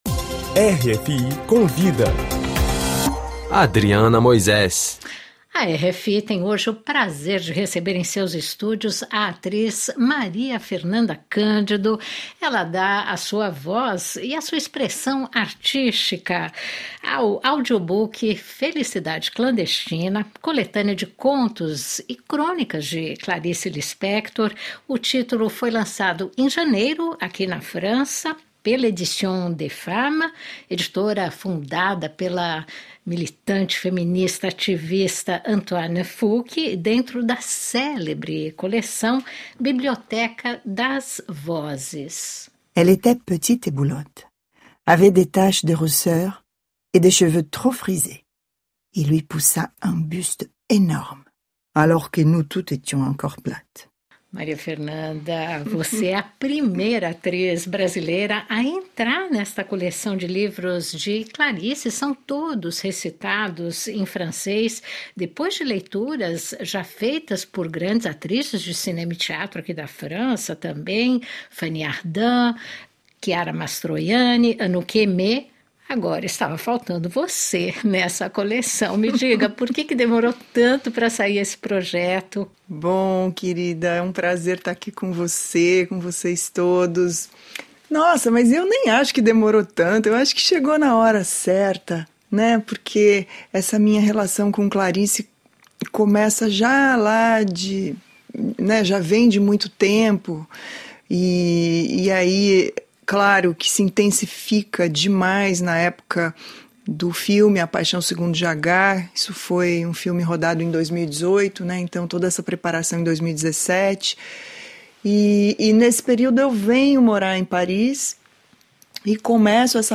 Maria Fernanda disse à RFI que o convite para integrar a coleção veio na hora certa.